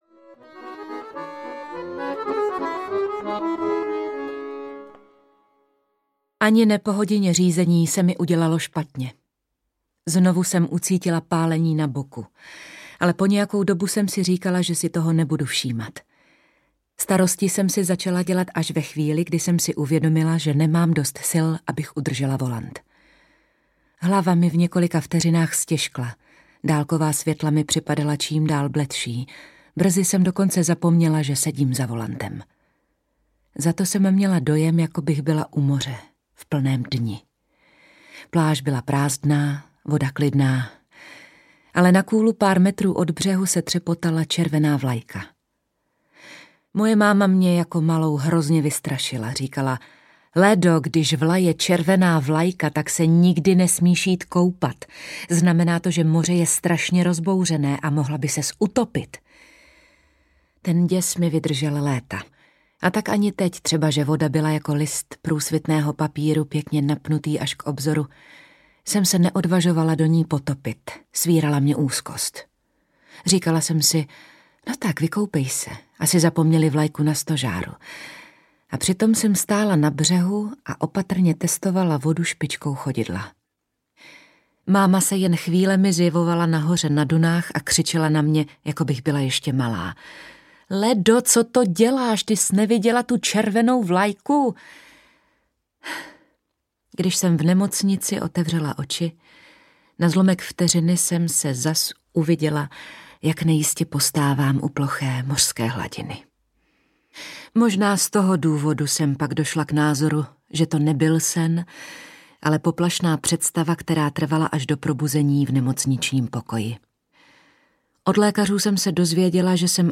Temná dcera audiokniha
Ukázka z knihy
• InterpretHelena Dvořáková